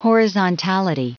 Prononciation du mot horizontality en anglais (fichier audio)
Prononciation du mot : horizontality